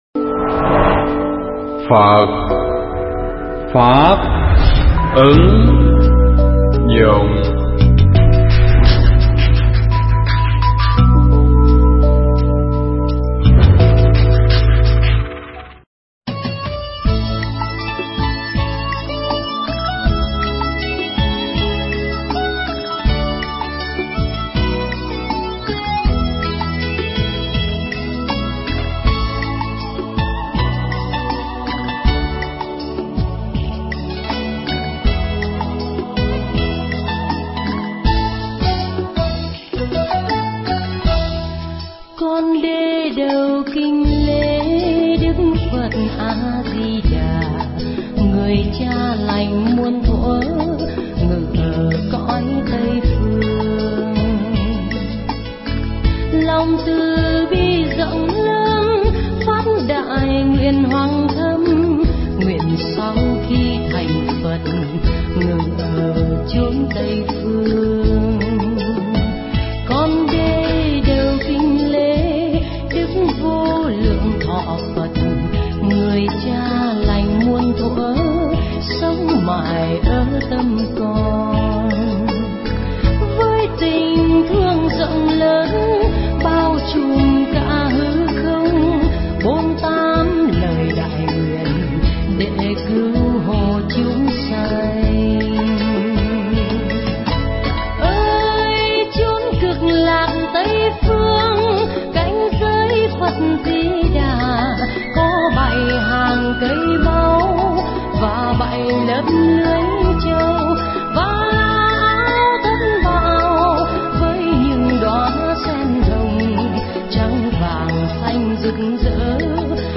Nghe Mp3 thuyết pháp Thính Pháp Theo Tinh Thần Phật Giáo
Mp3 pháp thoại Thính Pháp Theo Tinh Thần Phật Giáo